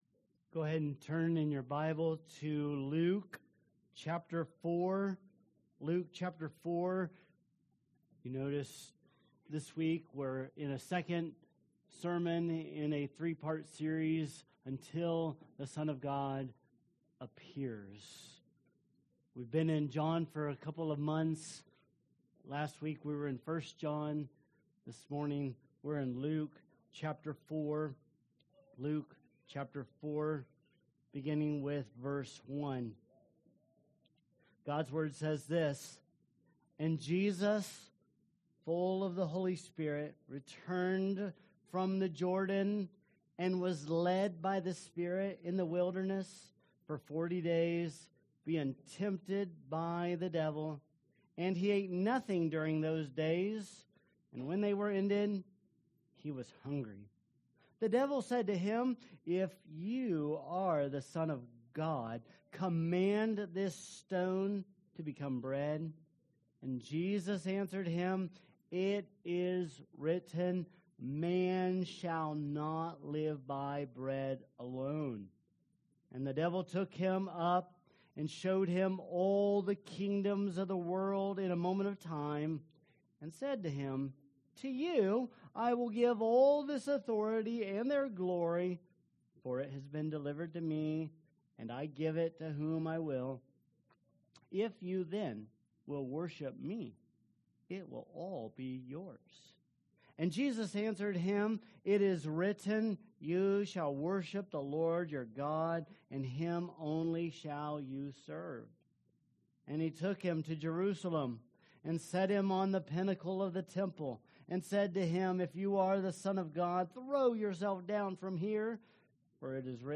Sermons | Vine Community Church